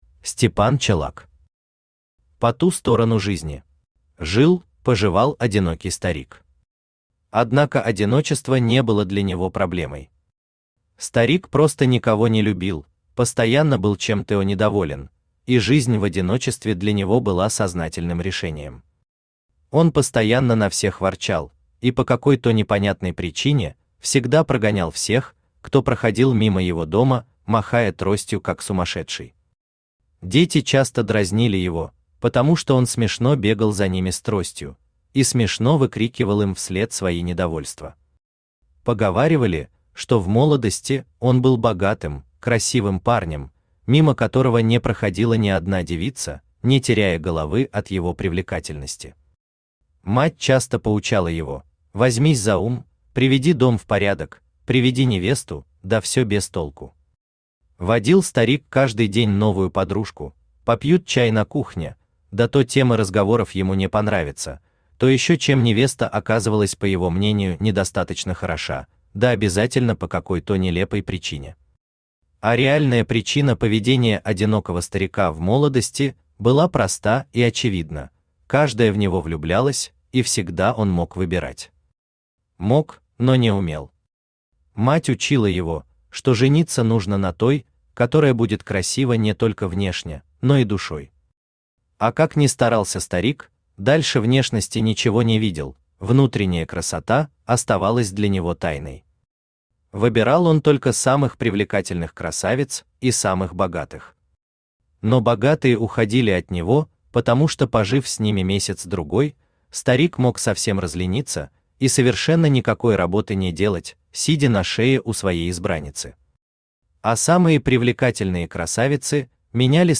Аудиокнига По ту сторону жизни | Библиотека аудиокниг
Aудиокнига По ту сторону жизни Автор Степан Дмитриевич Чолак Читает аудиокнигу Авточтец ЛитРес.